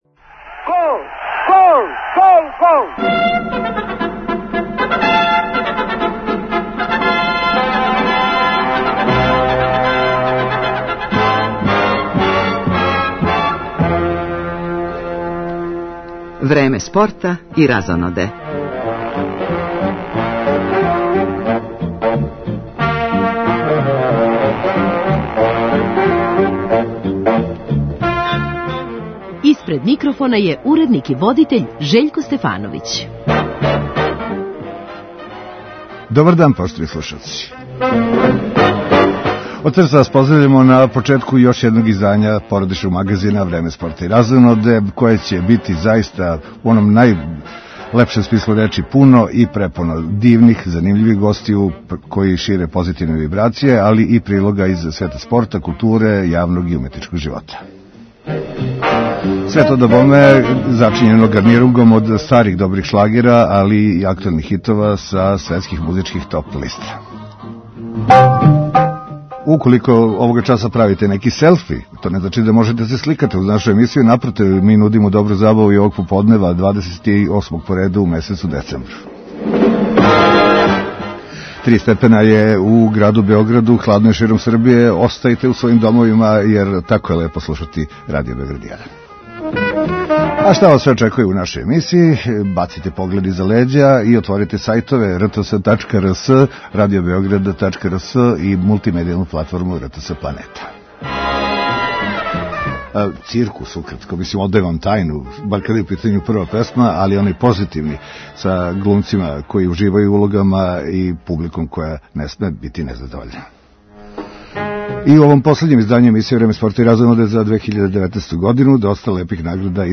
Овог поподнева биће нам драга гошћа у студију Радио Београда 1.